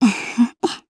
Gremory-Vox_Happy1_jp.wav